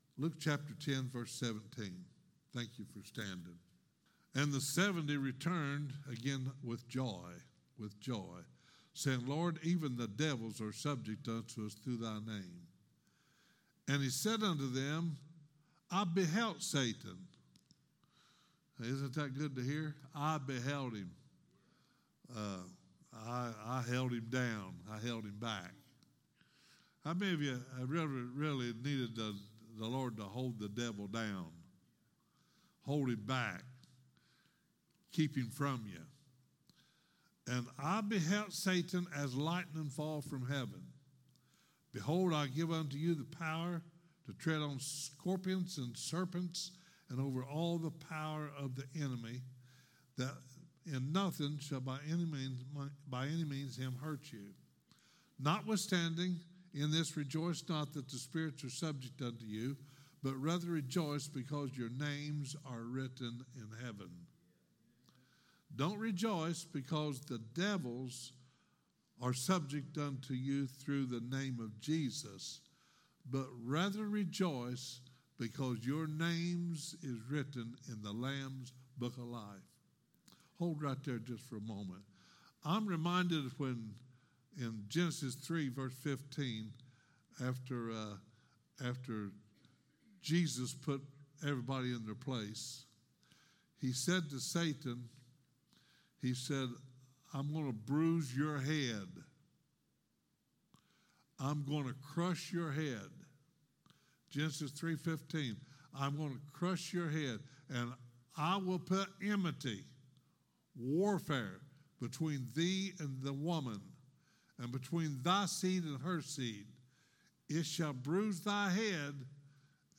Sermons | Metamora Church of God